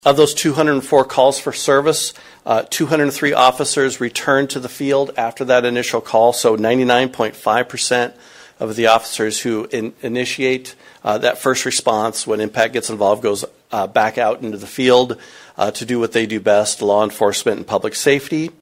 MPACT Quarterly Report at Marshalltown City Council Meeting | News-Talk 1230 KFJB